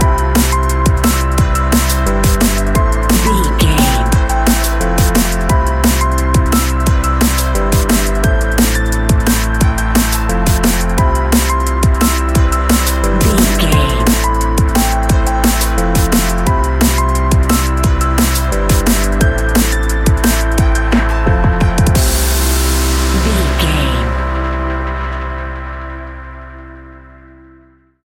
Ionian/Major
Fast
driving
uplifting
lively
futuristic
hypnotic
industrial
drum machine
synthesiser
electric piano
electronic
sub bass
synth leads
synth bass